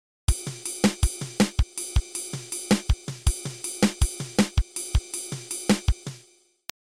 This loop was composed in Virtual Drummer. It feels linear to me because every instrument is struck individually, so the time is felt as the sound moves around the kit. Only on one beat are two sound sources struck simultaneously.
I composed this as 4 sets of 4 beats, and my goal was to come up with 4 different combinations of kick/snare rhythms under a slightly off-kilter ride groove (1 + 2 3 + 4).
Played slowly, this groove takes on a hypnotic feel.